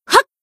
BA_V_Rumi_Battle_Shout_1.ogg